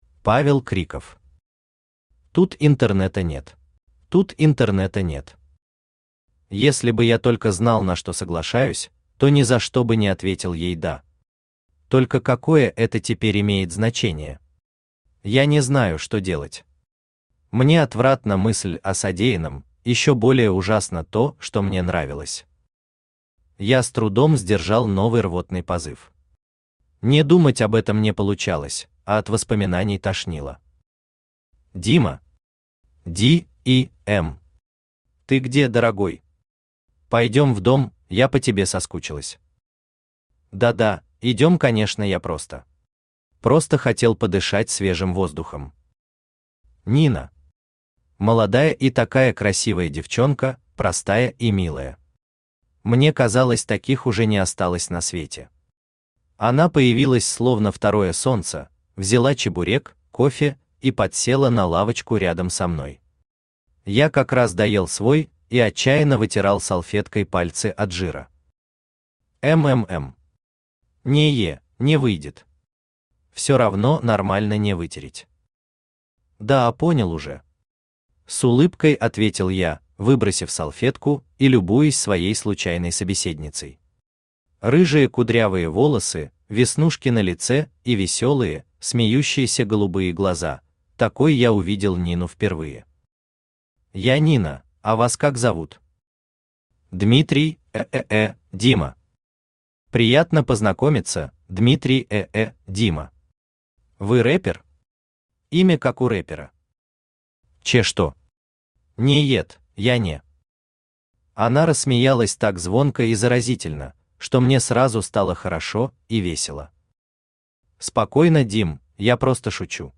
Аудиокнига Тут интернета нет | Библиотека аудиокниг
Aудиокнига Тут интернета нет Автор Павел Криков Читает аудиокнигу Авточтец ЛитРес.